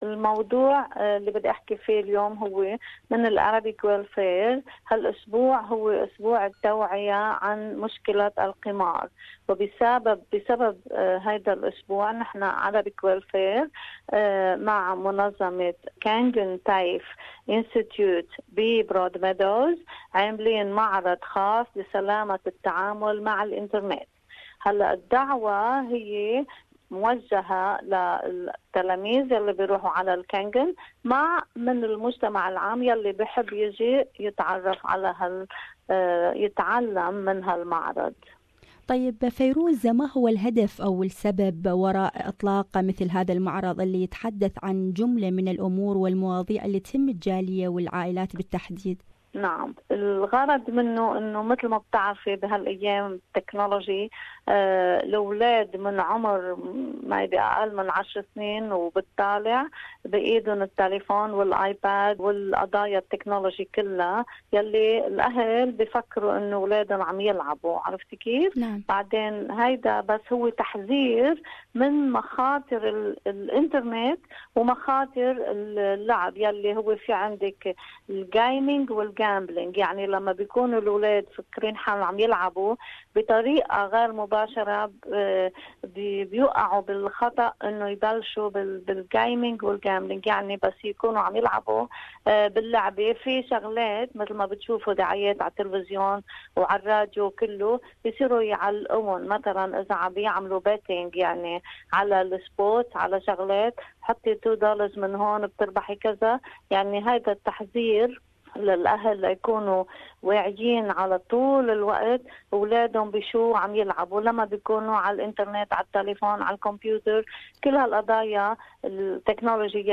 SBS Arabic